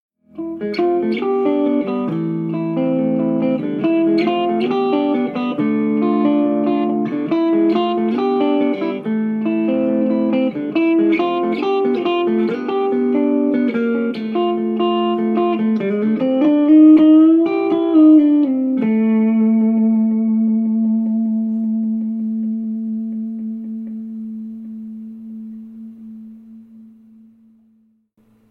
Sound eines mit Gain auf 12, Bass auf 12, Treble auf 12, Reverb auf 9, Volume auf 9, Gitarre , Hals PU, Volumen und Tone Poti beide ganz offen